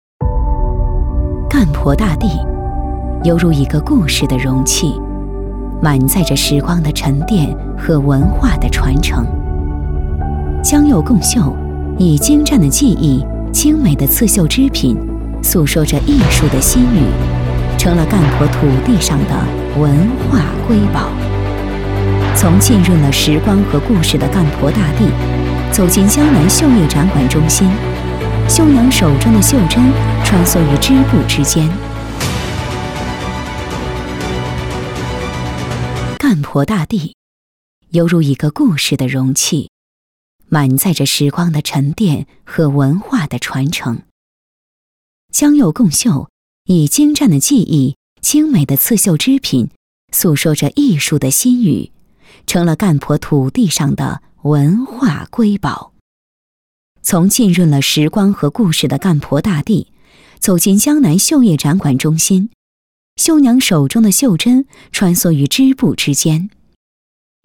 纪录片-女17-成熟-人文纪录.mp3